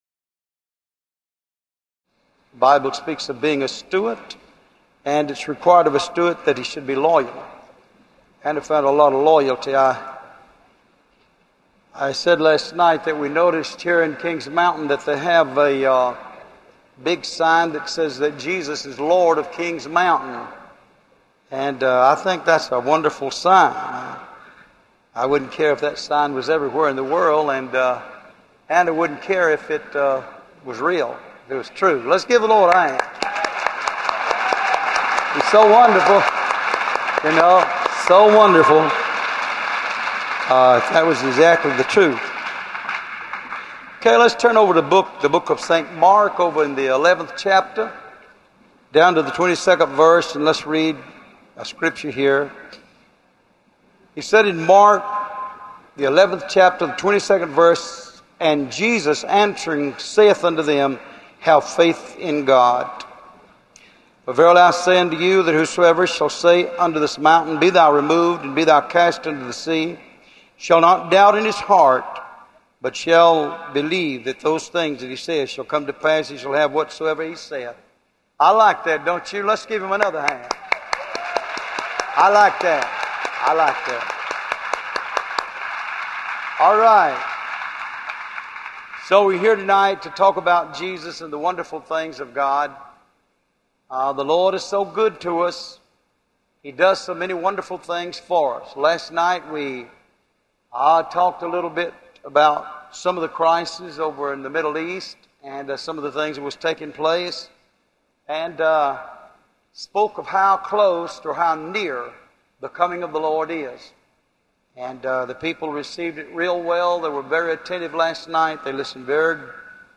Location: King’s Mountain, NC USA
Sermons